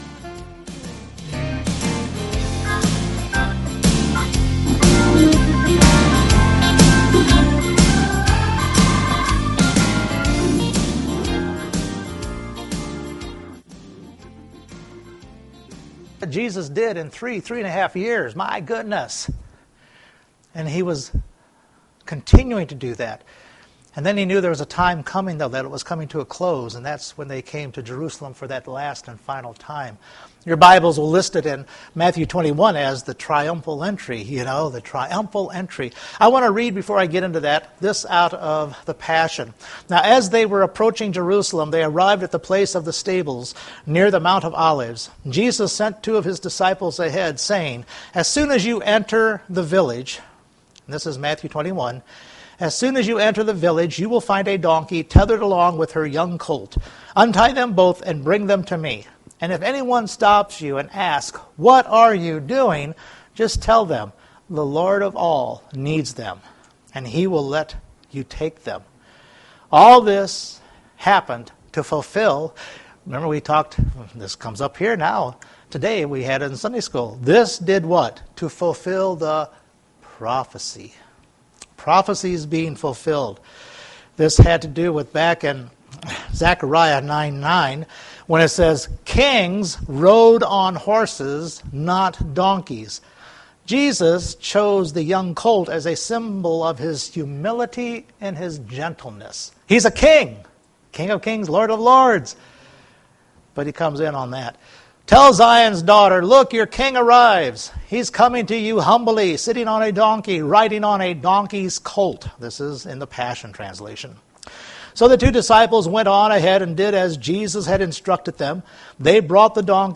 Matthew 21 Service Type: Sunday Morning Jesus is entering for the final time before going to the cross to fulfill God’s plan.